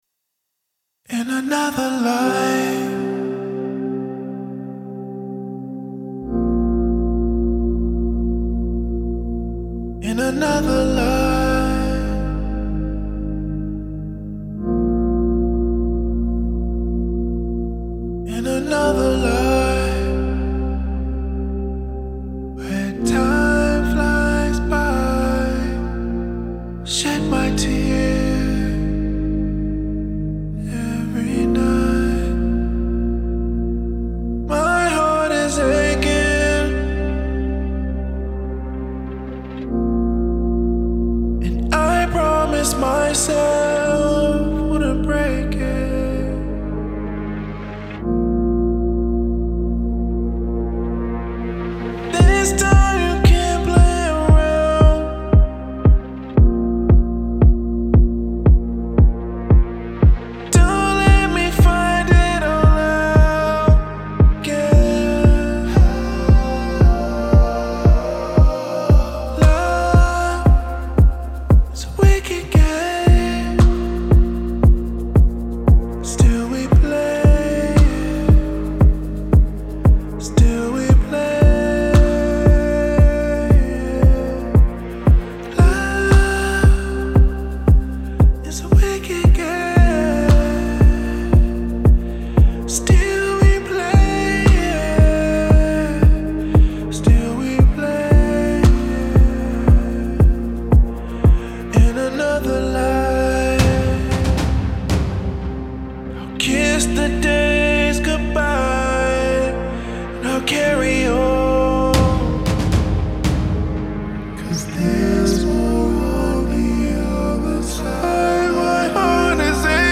alternative R&B